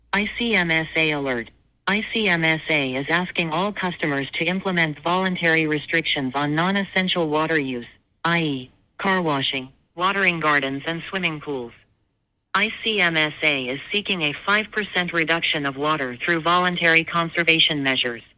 Below is the automated voicemail sent to customers Monday afternoon:
icmsa-phone-notice.wav